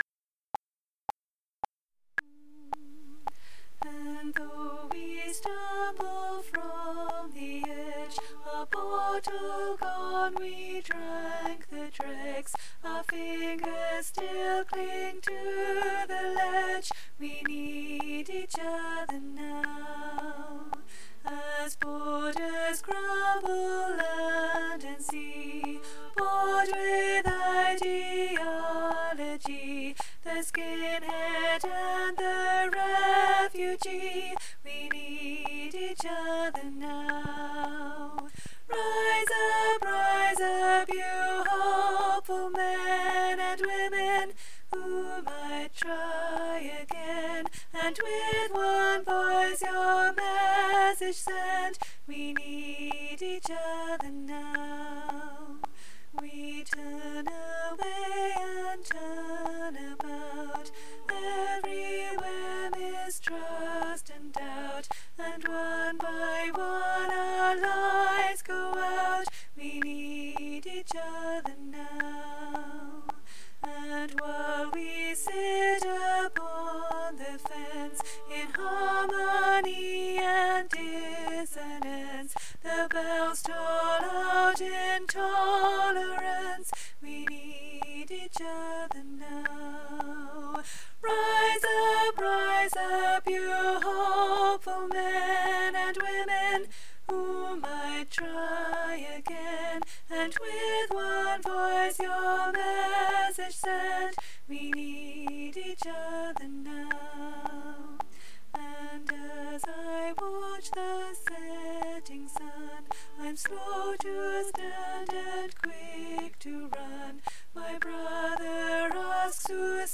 We Need Each Other Now ALTO